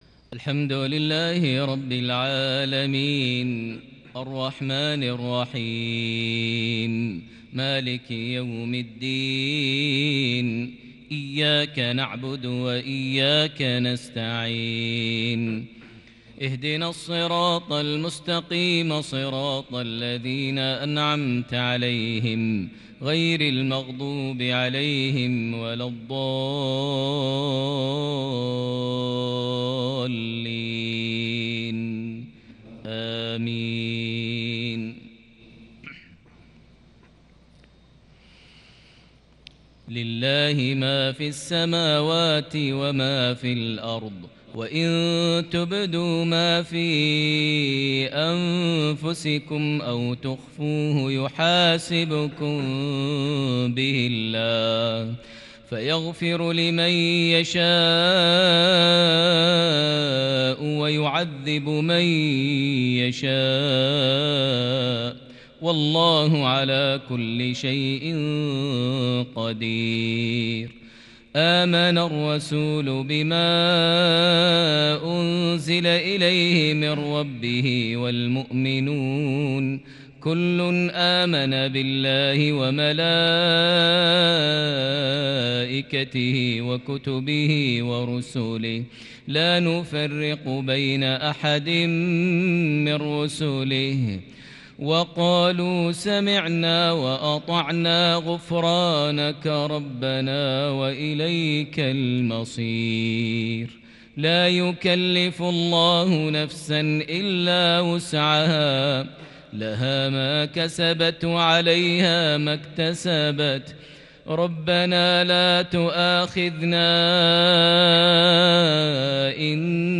انتقالات تأسرك لخواتيم سورتي البقرة - التوبة |مغرب 5 صفر 1442هــ > 1442 هـ > الفروض - تلاوات ماهر المعيقلي